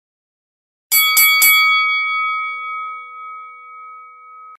Tiếng chuông bắt đầu Chiến đấu, Đấm bốc, Mở đầu Trò chơi…
Thể loại: Đánh nhau, vũ khí
Description: Hiệu ứng âm thanh tiếng chuông đấm bốc trận đấu quyền anh, bắt đầu chiến đấu, mở đầu trò chơi, bắt đầu cuộc thi..., Boxing Bell Sound Effect, wrestling ring Bell, boxing match, Hiệu ứng âm thanh chuông đấu vật SFX...
tieng-chuong-bat-dau-chien-dau-dam-boc-mo-dau-tro-choi-www_tiengdong_com.mp3